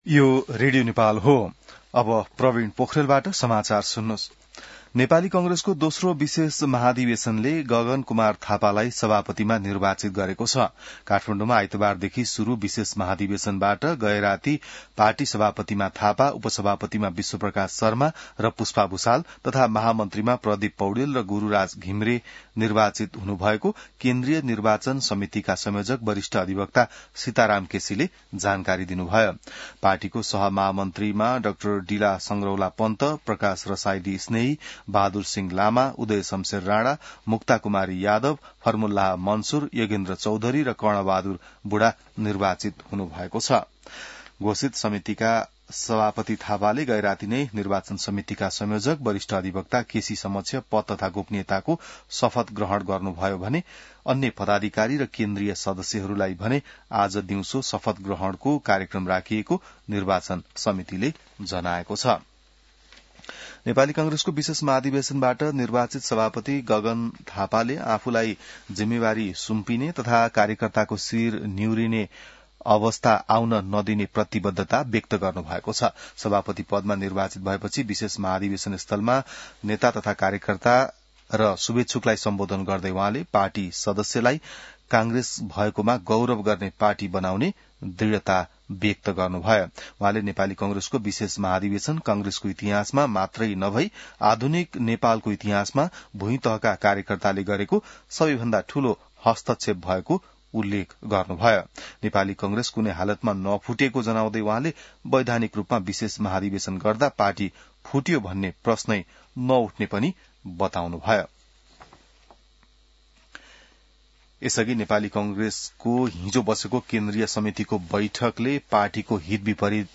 An online outlet of Nepal's national radio broadcaster
बिहान ६ बजेको नेपाली समाचार : १ माघ , २०८२